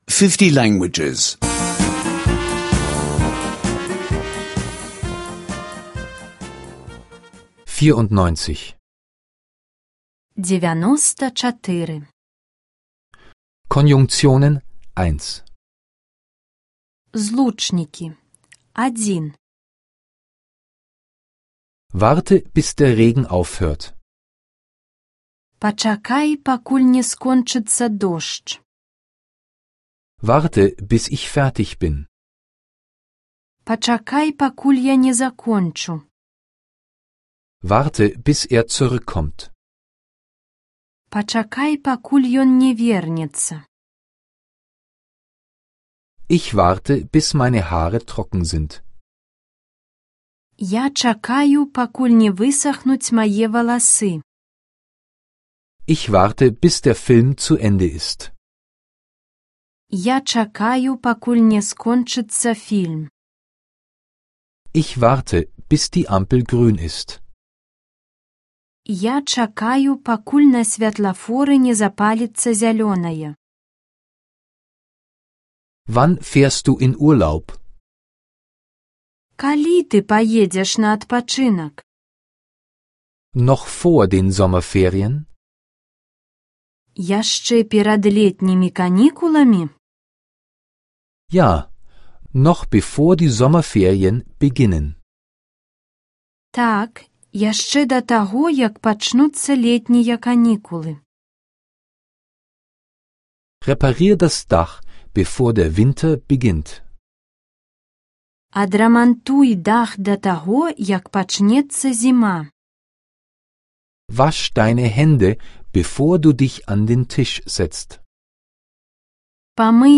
Weißrussisch Sprache-Audiokurs (online anhören)